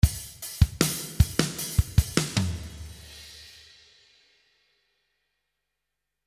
77 BPM - Little Story (23 variations)
Drum beat loops in 77 bpm.
This beat is with big snare, playing with close hihat, open hihat and beat with playing on the crash overheads.
Qty: $5.00 Pop drum beat in 77 bpm.